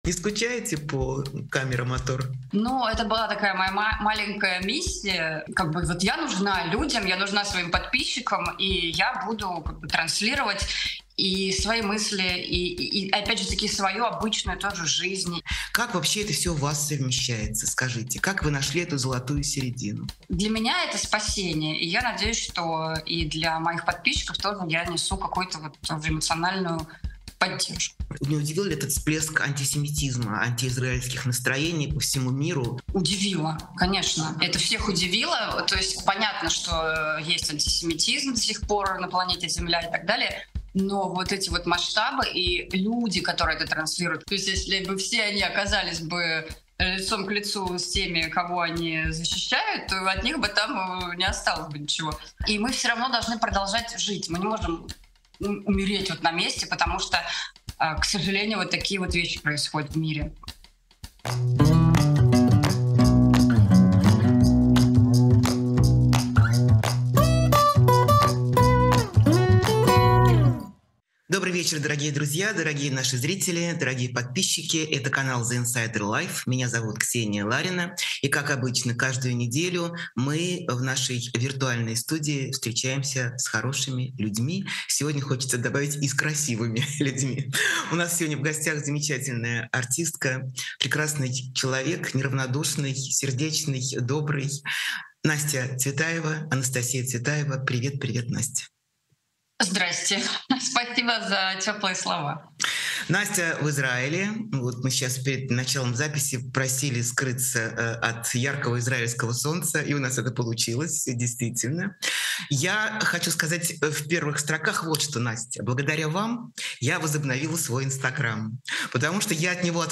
Эфир ведёт Ксения Ларина
Новый выпуск программы «честно говоря» с Ксенией Лариной.